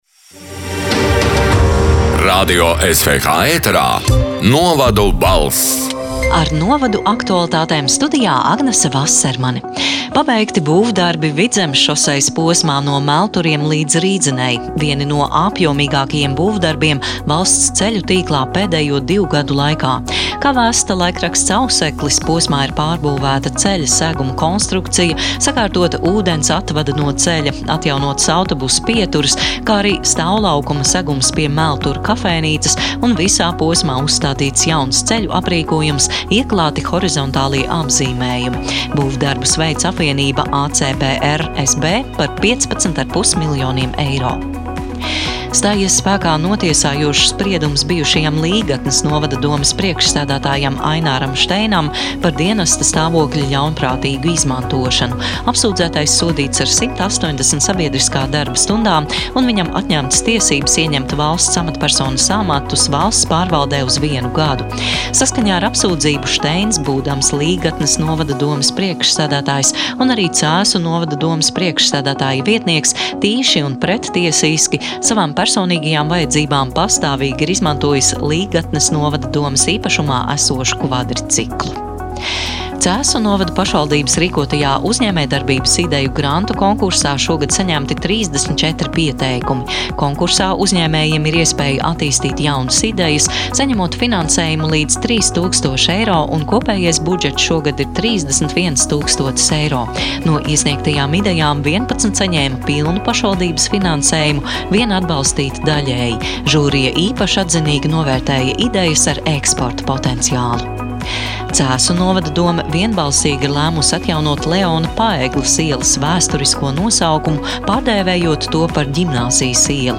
“Novadu balss” 13. novembra ziņu raidījuma ieraksts: